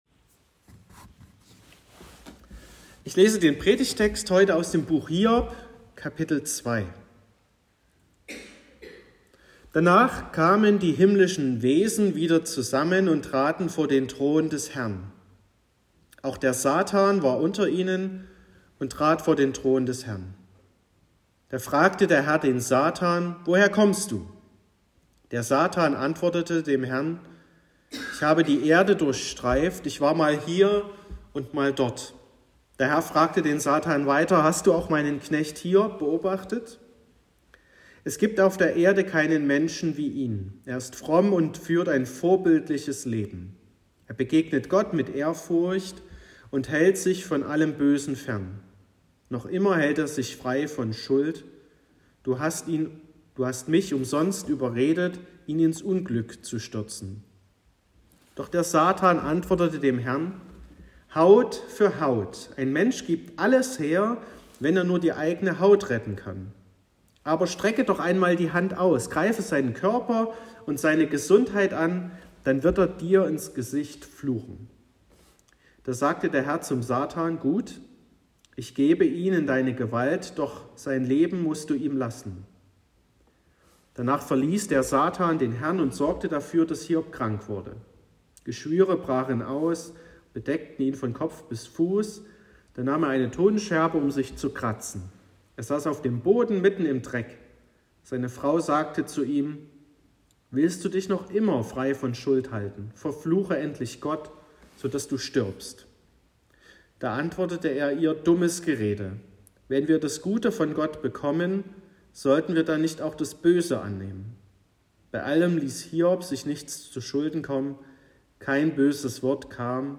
26.02.2023 – Gottesdienst
Predigt (Audio): 2023-02-26_Durch_Leid_gehen.m4a (8,5 MB)